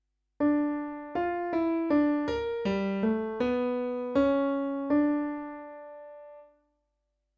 Practice finding the tonic in a minor key
Question 1: C